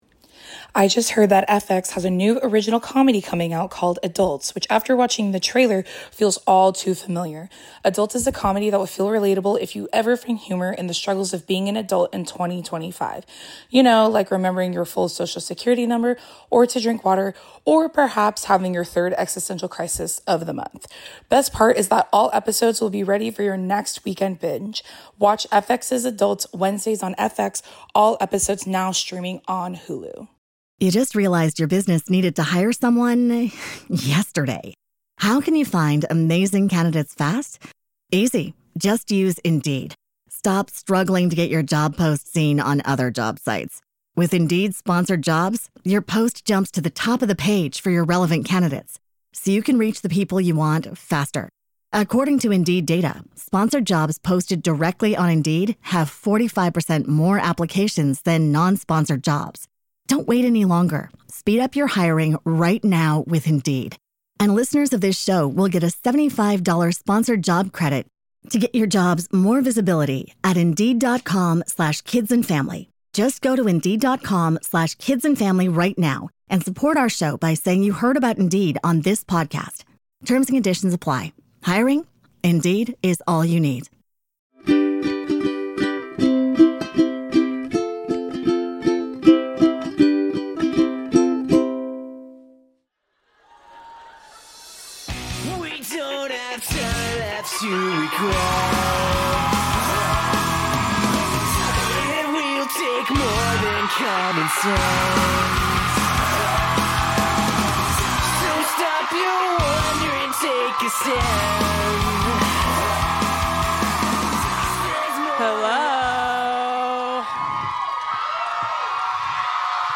LIVE FROM LOS ANGELES, IT'S TWO IDIOT GIRLS!!!!!
SURPRISE!!! here's the live recording of our recent show in Los Angeles, California and so many of you lil freaks got to be there in person to see it! we know so many of you couldn't make it so we made sure to record one of our best shows so everyone could enjoy it...